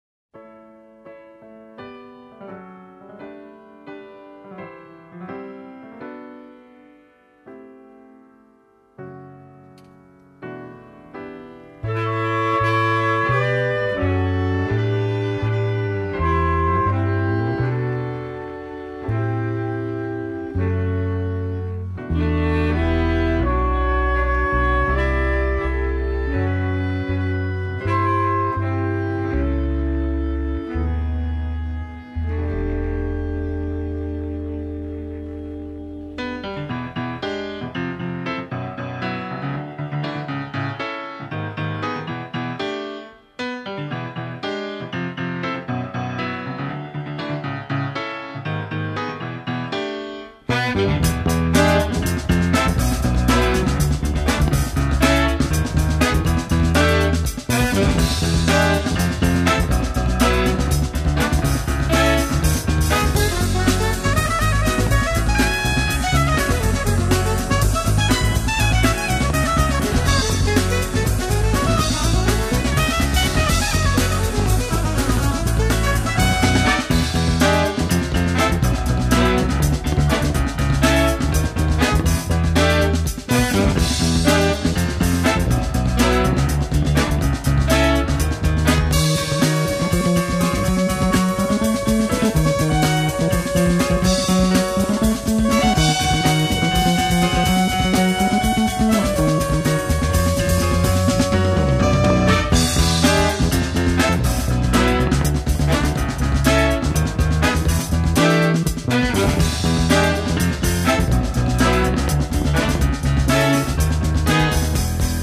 newly remastered